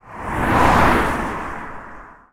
car3.wav